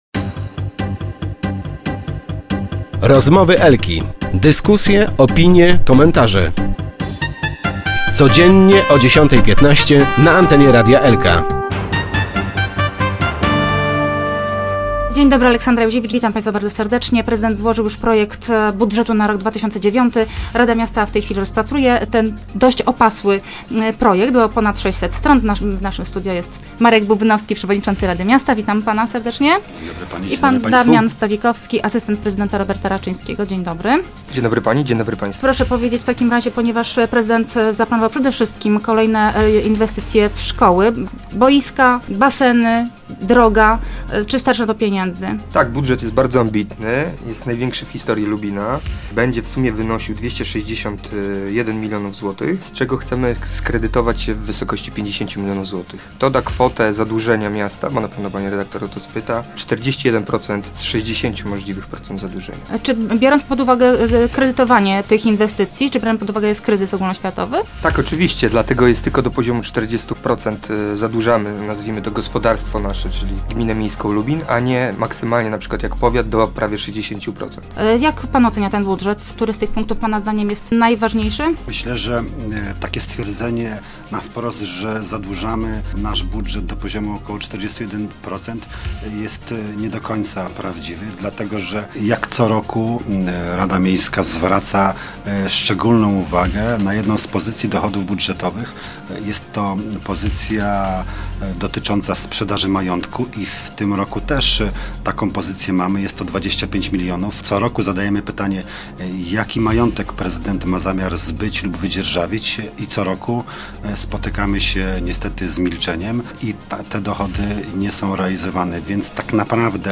Goście: Marek Bubnowski - przewodniczący rady miejskiej Lubina